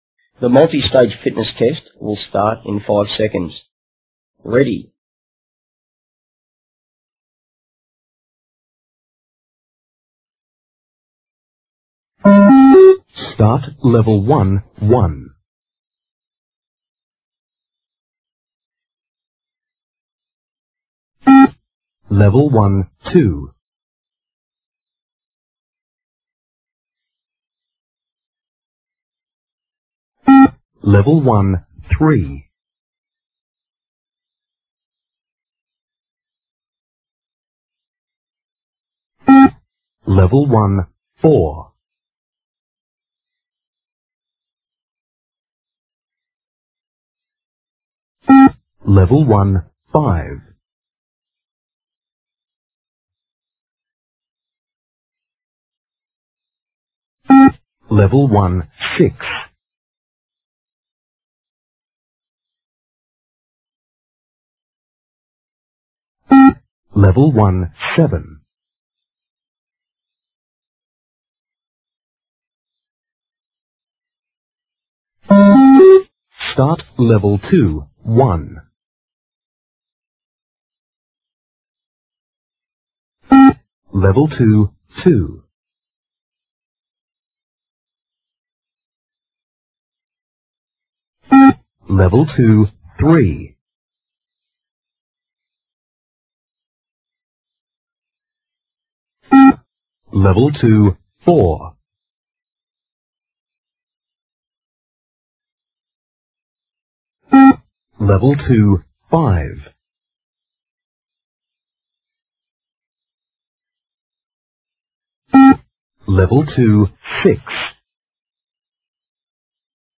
shuttle run .beep-test.mp3
shuttle-run-.beep-test.mp3